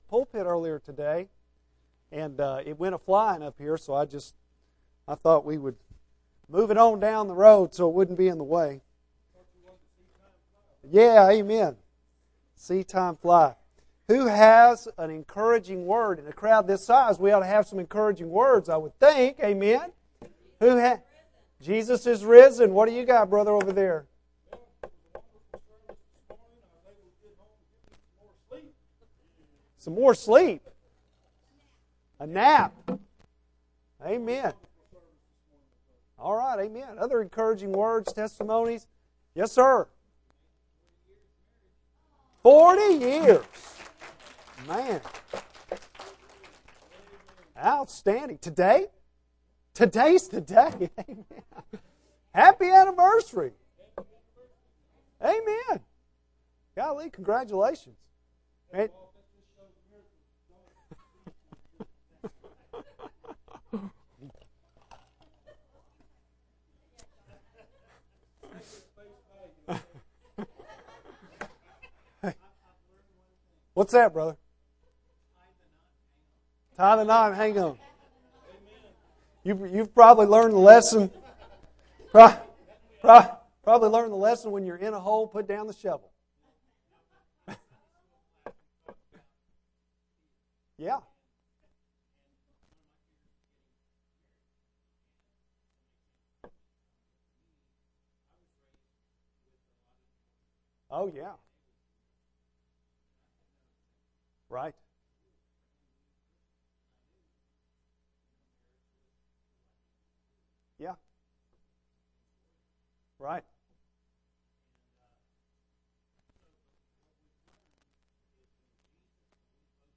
Bible Text: Joshua 1:1-9 | Preacher